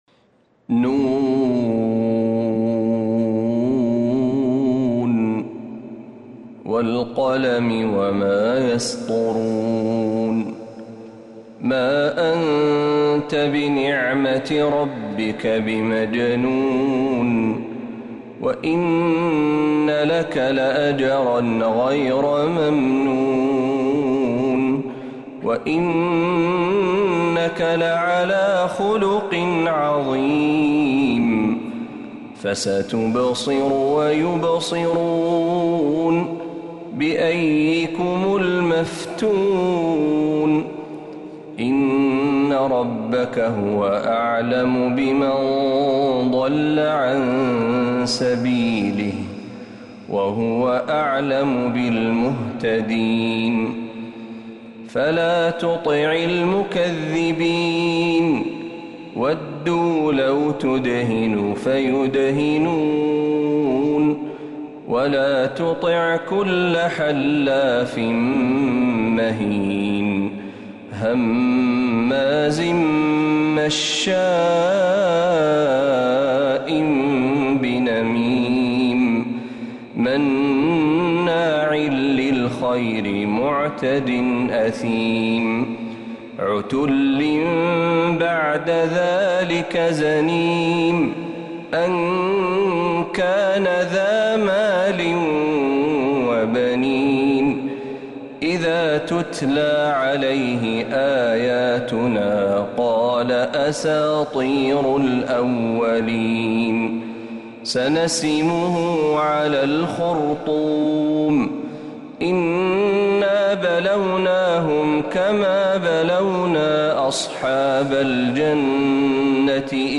سورة القلم كاملة من الحرم النبوي